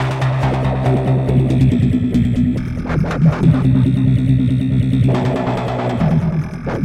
科幻音效库 " 翘曲驱动器02
描述：用于科幻游戏的综合高科技经线驱动声音。当宇宙飞船比光行程更快地启动时有用。